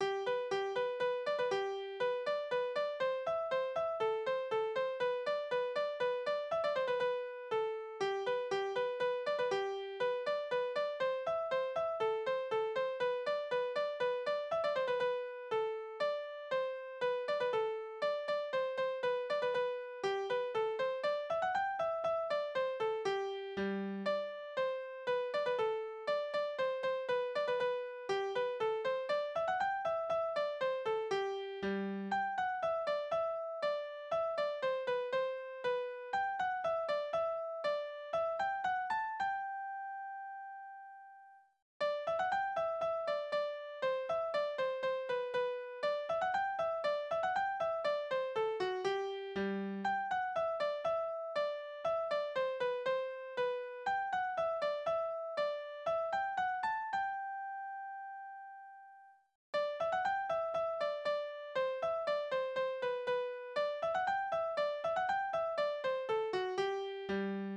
Küssertanz Tanzverse
Tonart: G-Dur Taktart: 2/4 Tonumfang: große Sekunde über zwei Oktaven Besetzung: instrumental
Vortragsbezeichnungen: langsamer, a tempo